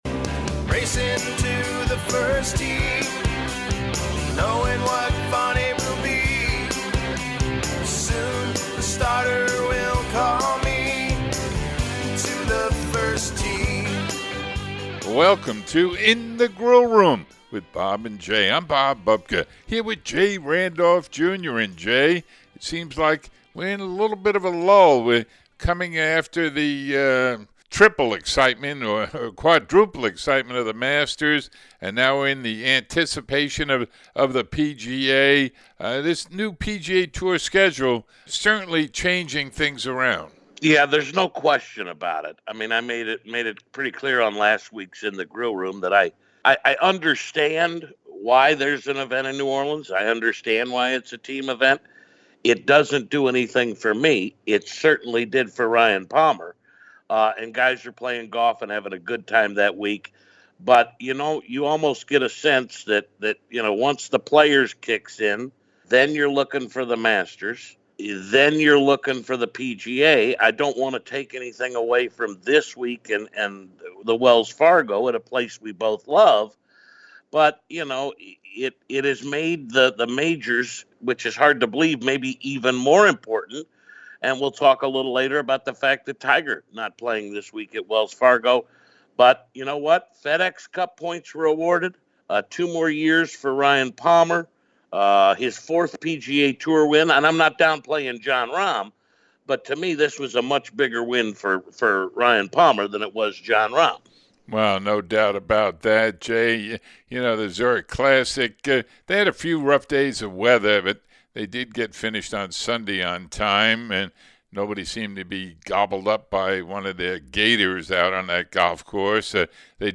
Feature Interview